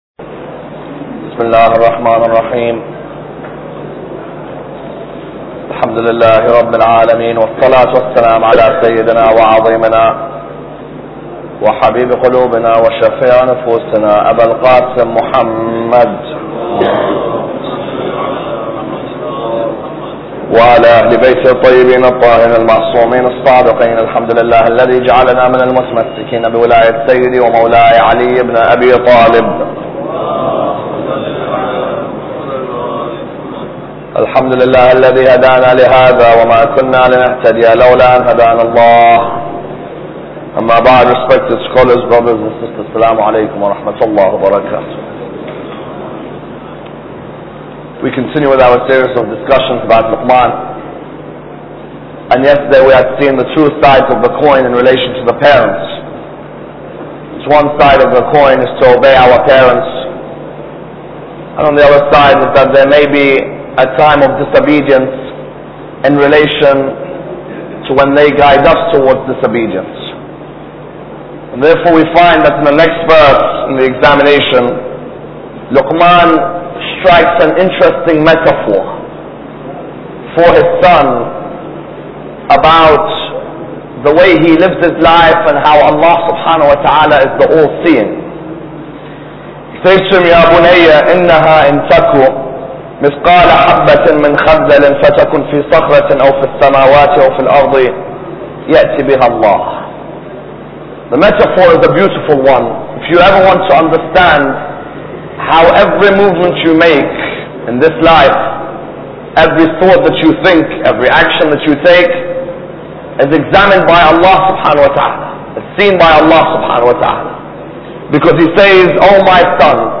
Lecture 10